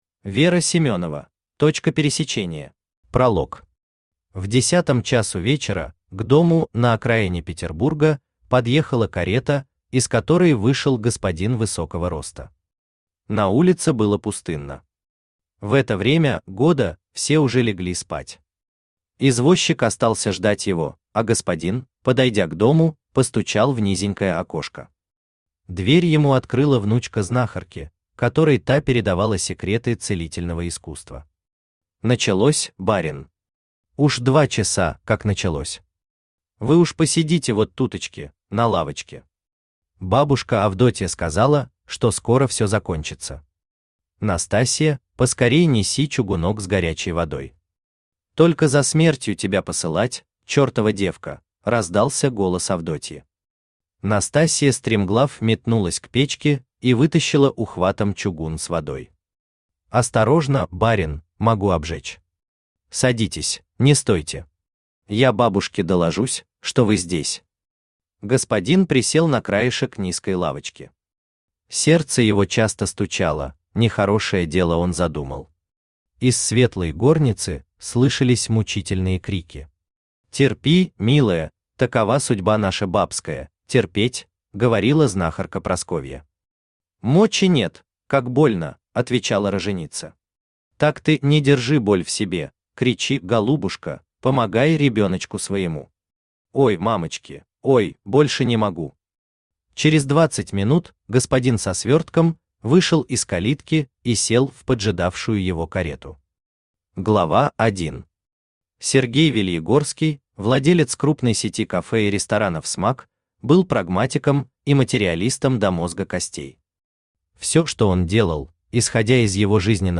Аудиокнига Точка пересечения | Библиотека аудиокниг
Aудиокнига Точка пересечения Автор Вера Семенова Читает аудиокнигу Авточтец ЛитРес.